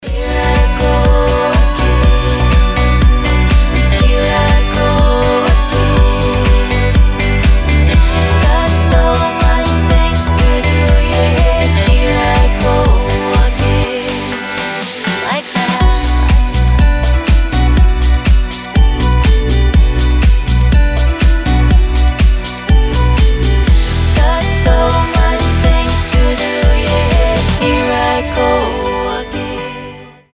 красивые
женский вокал
dance
Electronic
house